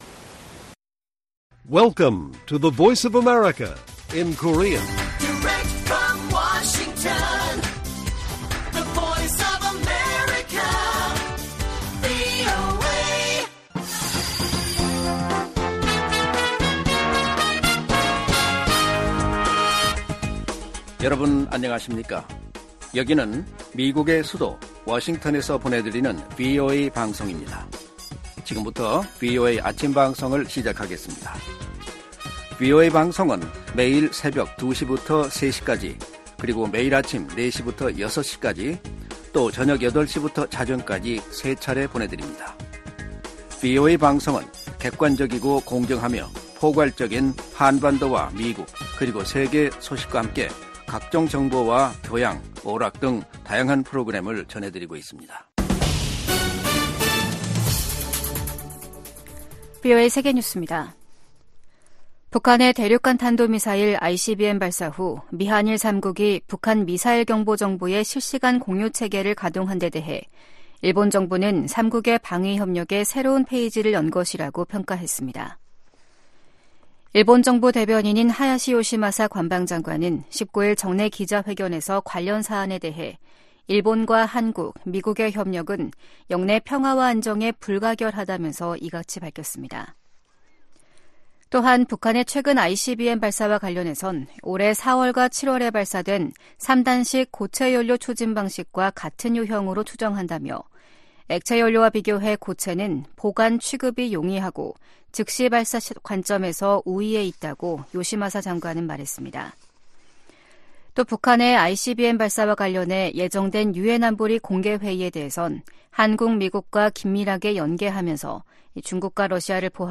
세계 뉴스와 함께 미국의 모든 것을 소개하는 '생방송 여기는 워싱턴입니다', 2023년 12월 20일 아침 방송입니다. '지구촌 오늘'에서는 미국이 홍해 통과 선박들을 보호하기 위해 다국적군 함대를 꾸리고 국제 공조를 강화하기로 한 소식 전해드리고, '아메리카 나우'에서는 도널드 트럼프 전 대통령이 내년 3월 중순 공화당 후보로 확정될 것으로 캠프 측이 전망하고 있는 이야기 살펴보겠습니다.